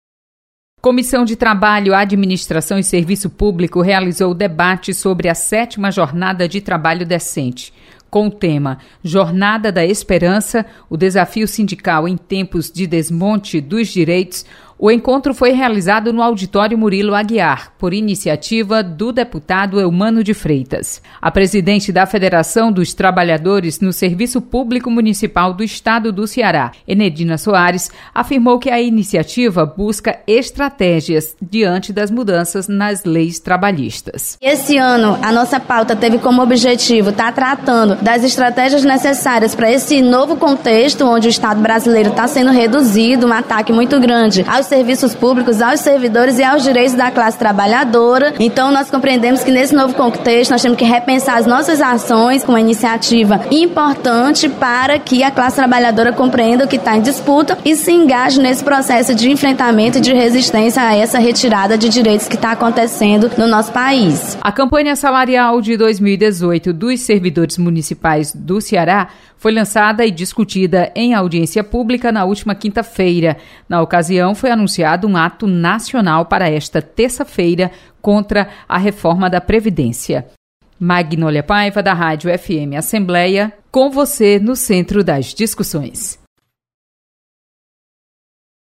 FM Assembleia